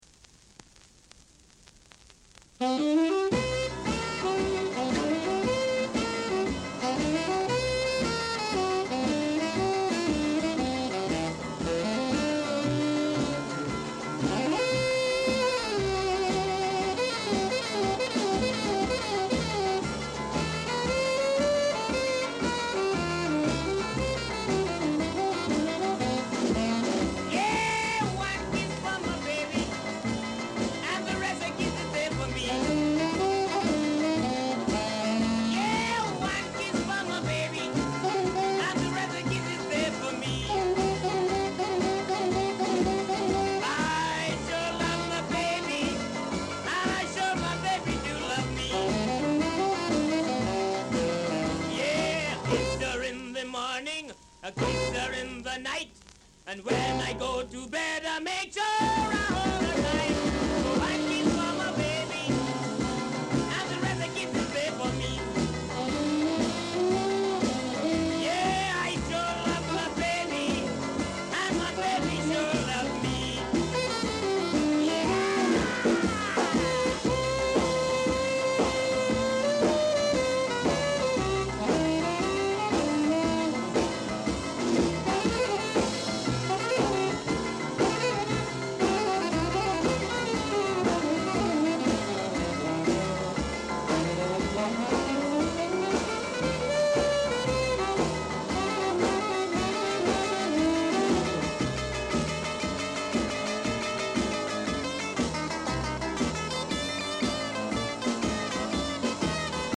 Blues Male Vocal
Rare! bad Ja blues vocal w-sider!
カリプソニアンが唄うハードブルーズ！